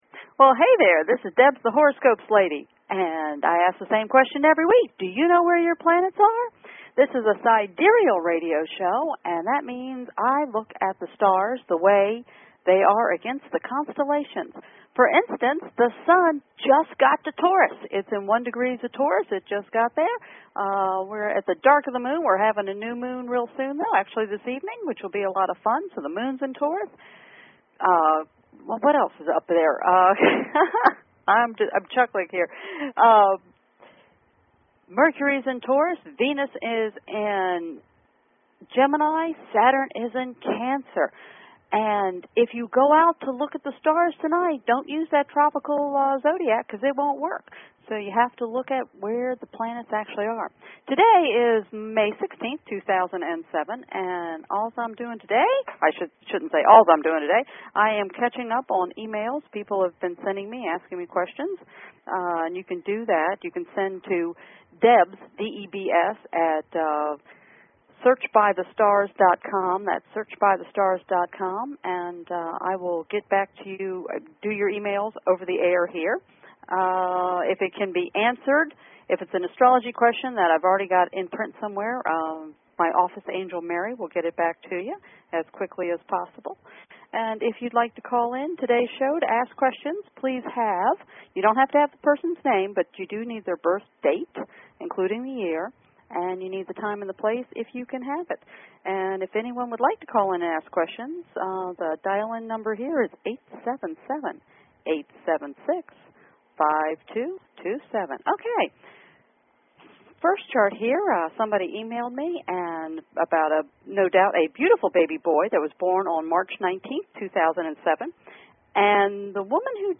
Talk Show Episode
Her guests include other leading sidereal astrologers and the occasional celebrity who has found sidereal astrology useful.
You can e-mail and hear your answers on air.